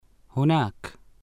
[hunaaka]